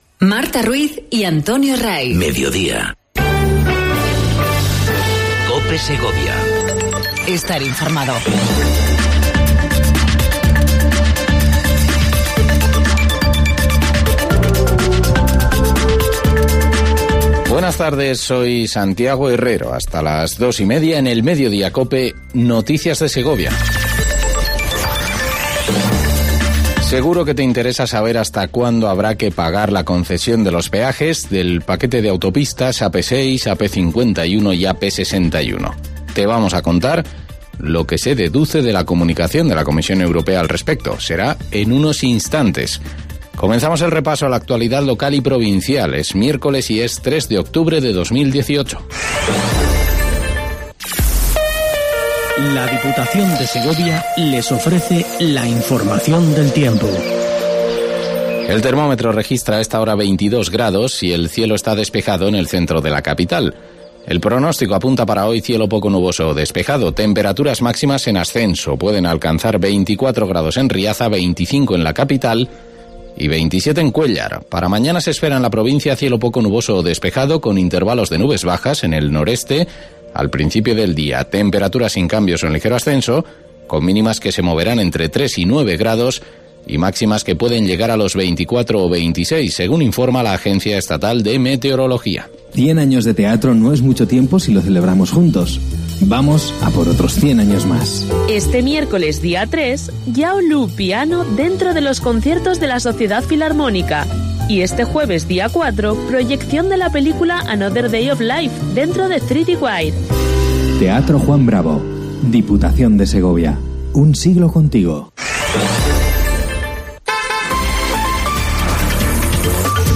INFORMATIVO MEDIODÍA COPE SEGOVIA 03 10 18